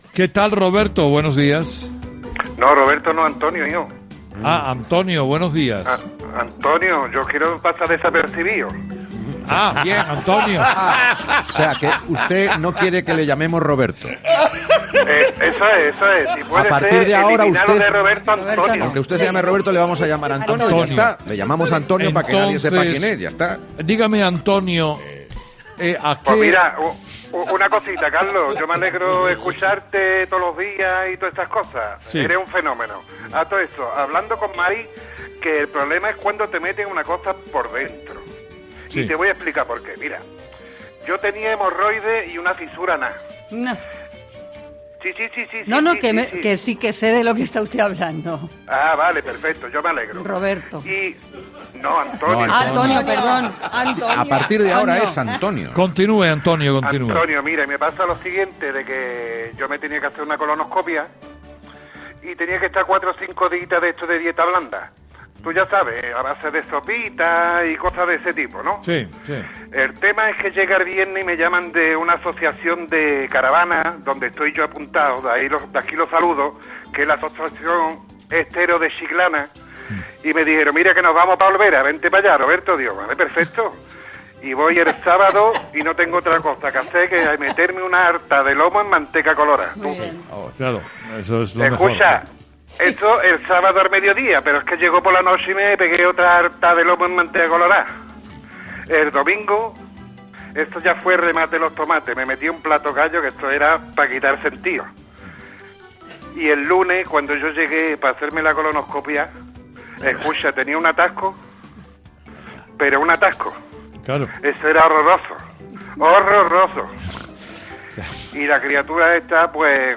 Este miércoles los oyentes han hablado de tratamientos médicos y ha surgido el debate: ¿Son las mujeres más tolerantes al dolor que los hombres?